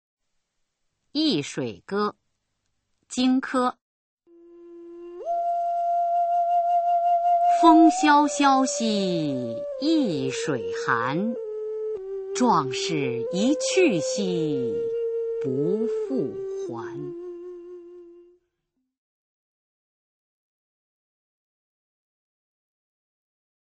[先秦诗词诵读]荆轲-易水歌 朗诵